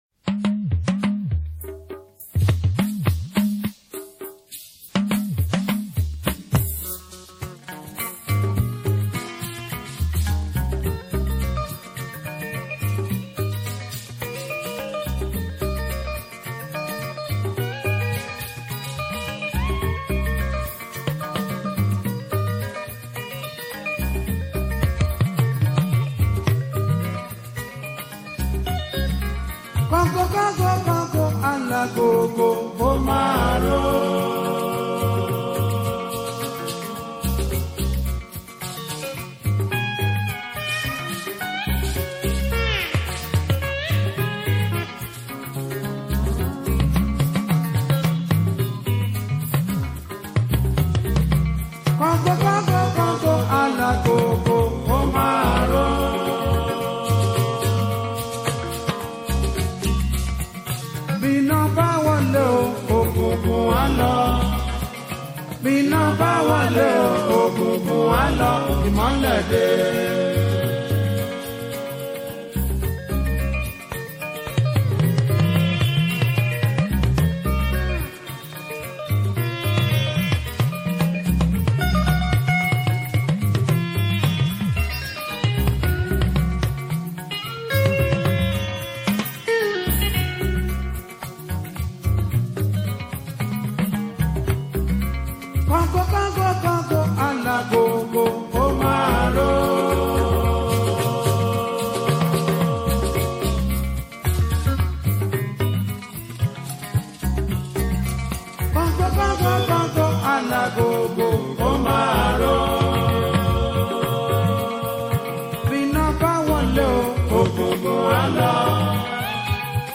As far as Yoruba Juju Music is concerned